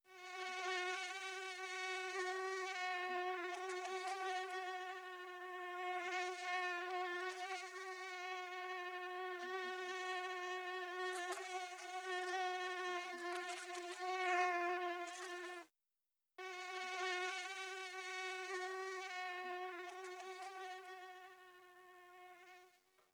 Insetto-zanzara-02.mp3